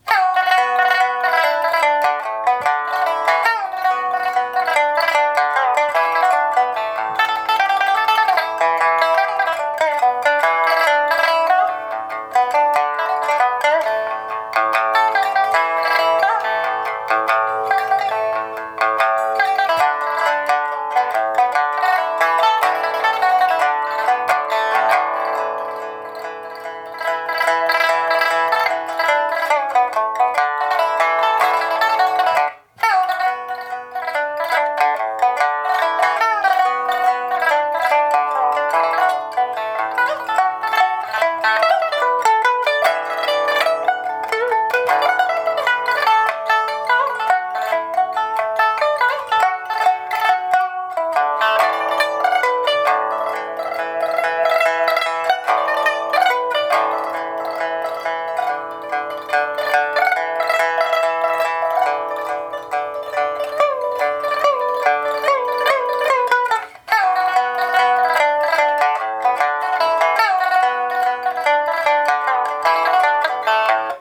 “爵士风”琵琶曲